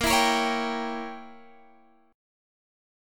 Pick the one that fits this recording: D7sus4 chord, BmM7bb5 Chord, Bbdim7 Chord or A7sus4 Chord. Bbdim7 Chord